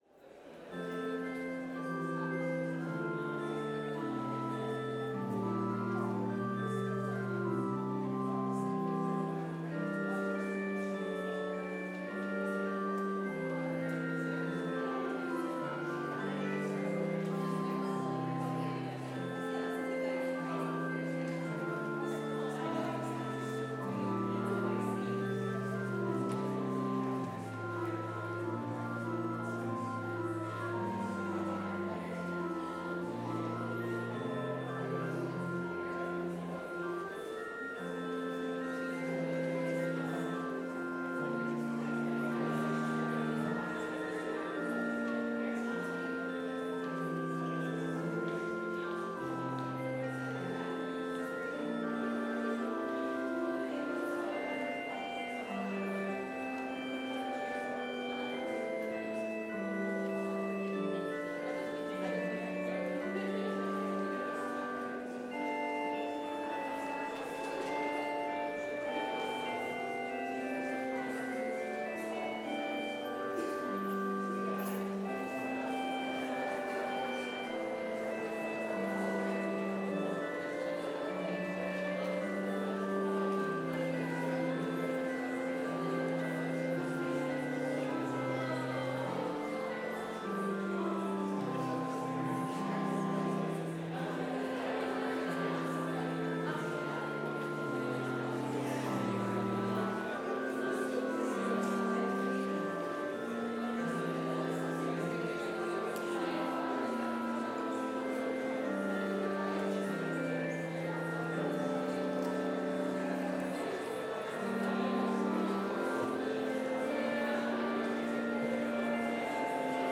Complete service audio for Chapel - Wednesday, October 23, 2024